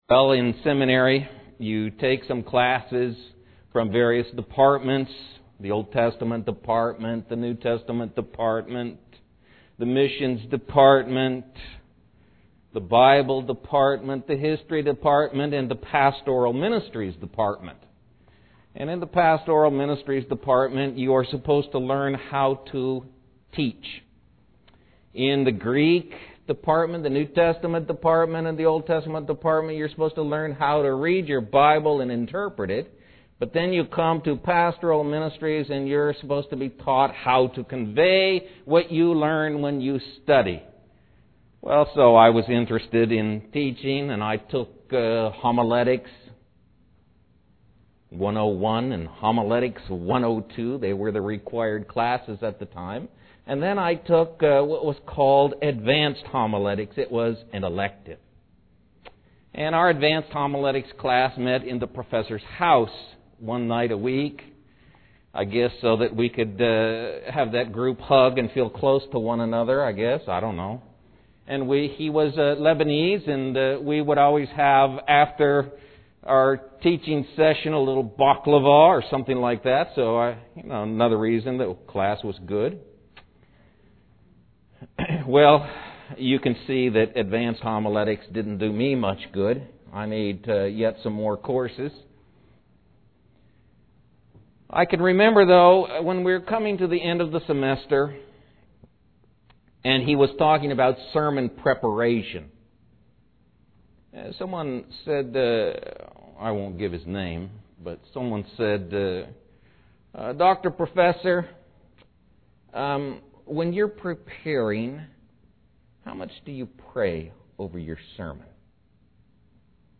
Explore our sermon library below to play, download, and share messages from McKinney Bible Church.